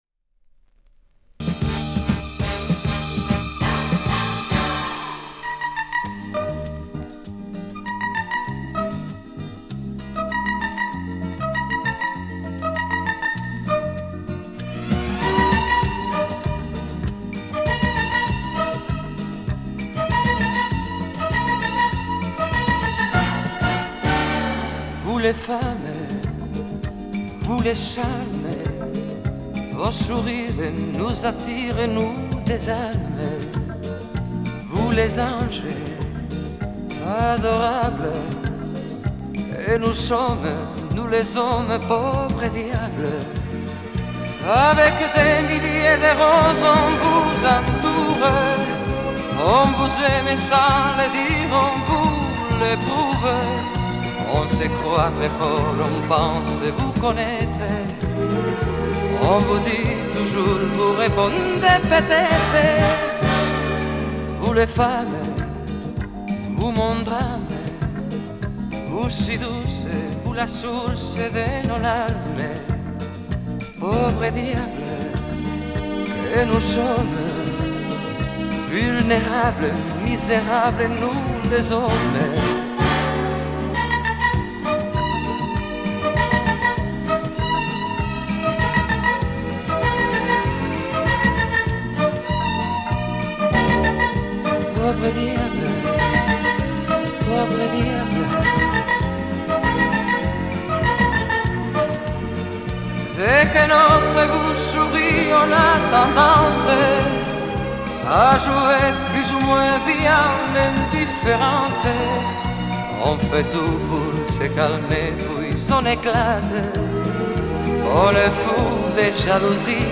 С винила: